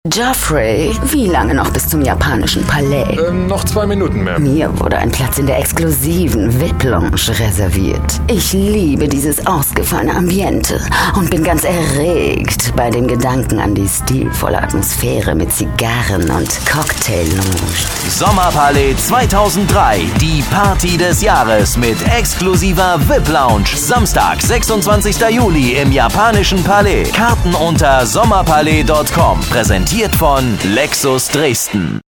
• Rundfunk-/Werbespots